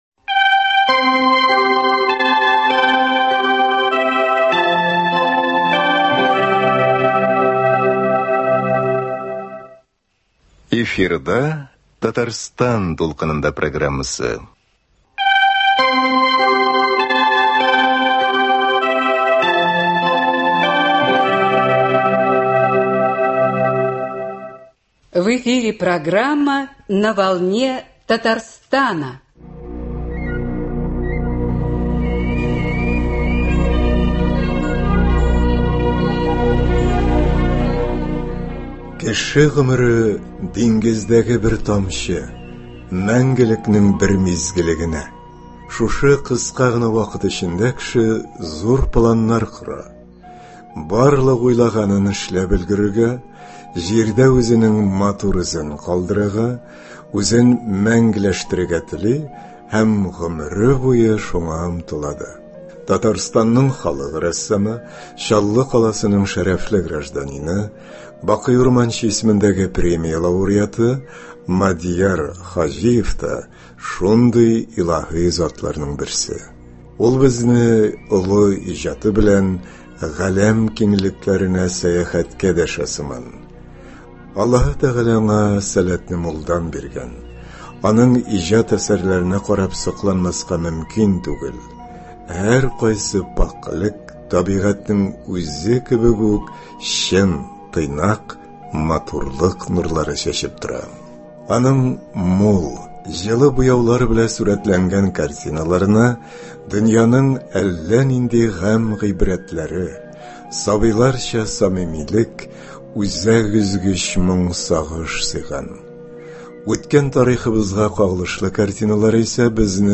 Студиябез кунагы Татарстанның халык рәссамы